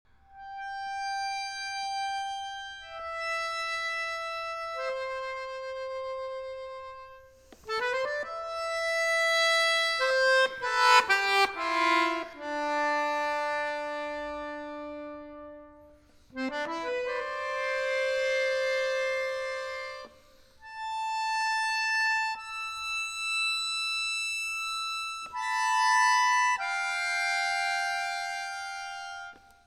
In this play the performer's voice must be heard.